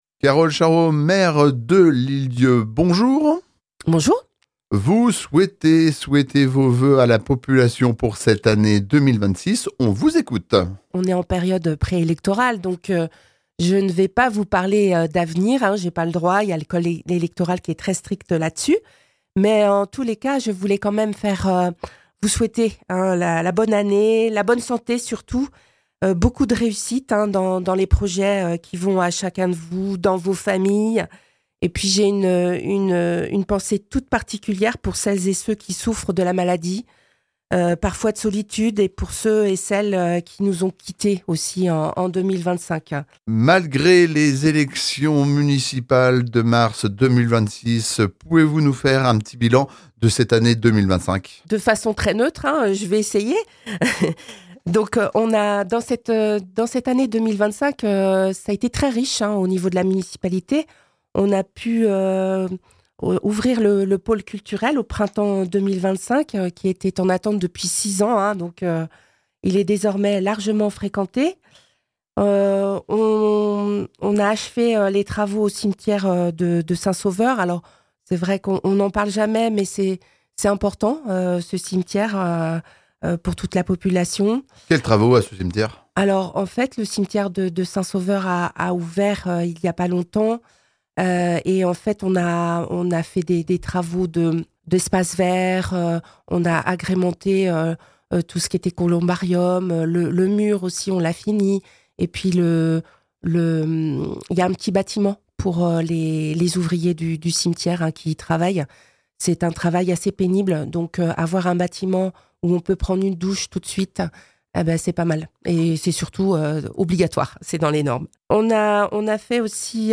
À l’occasion de ses vœux à la population, Carole Charuau, maire de l’Île d’Yeu, revient sur l’année 2025. En cette période électorale, pas question d’évoquer de nouveaux projets ou l’avenir de la commune : l’entretien se concentre donc sur un retour simple et factuel sur ce qui a été réalisé au cours de l’année écoulée.
itw-voeuxmaire2026.mp3